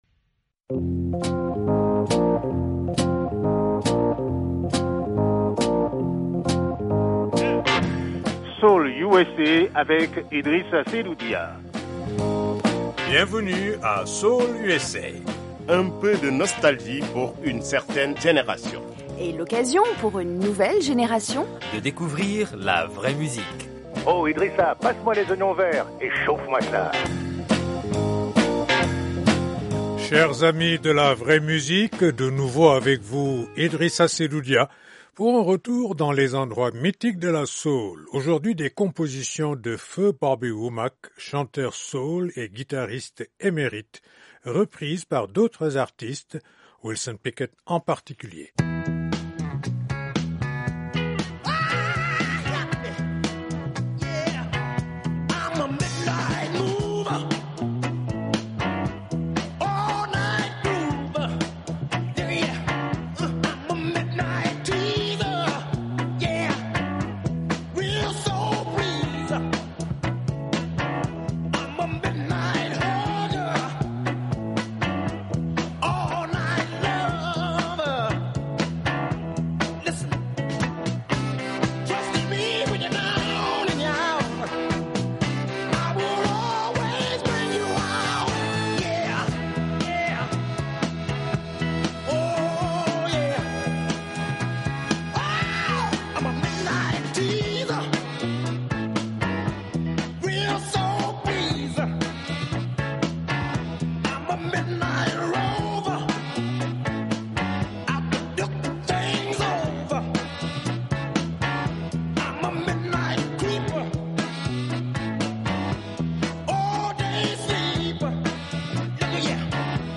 Les bons vieux tubes des années 60 et 70.